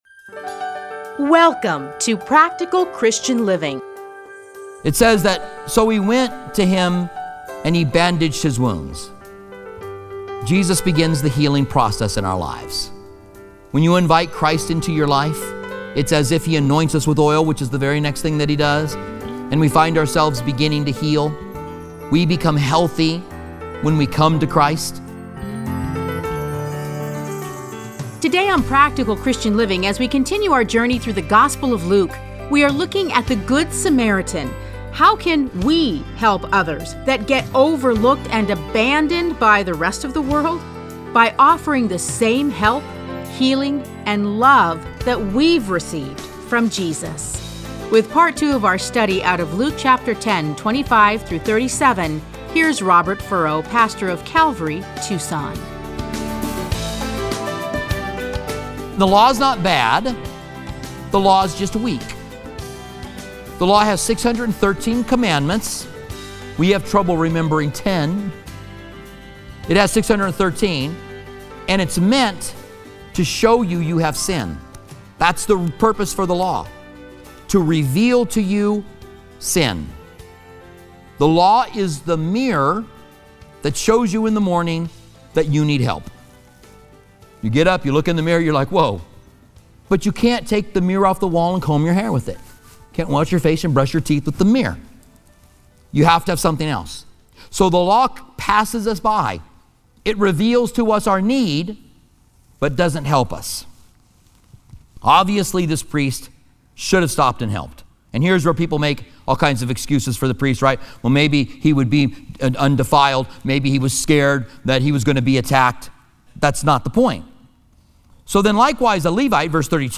Listen to a teaching from Luke Luke 10:25-37.